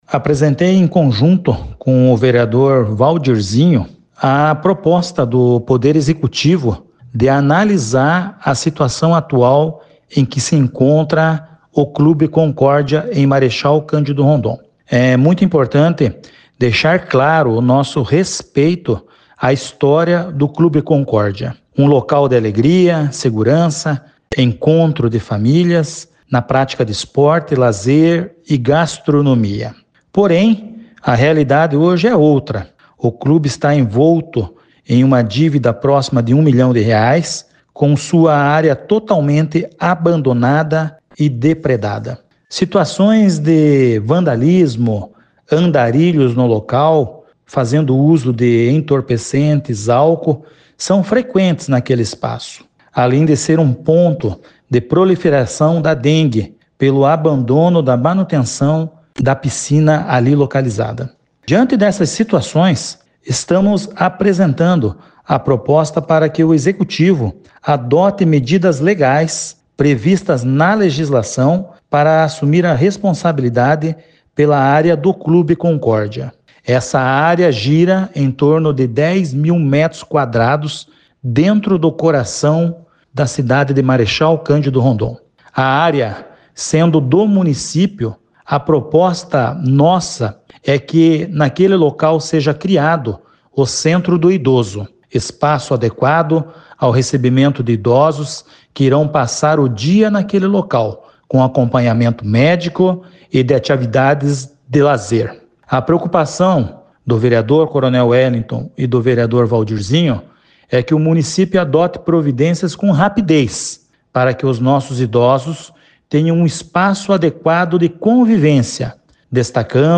O vereador coronel Welyngton fala da proposta relacionada ao antigo Clube Concórdia de Marechal Rondon……